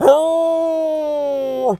wolf_hurt_03.wav